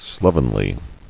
[slov·en·ly]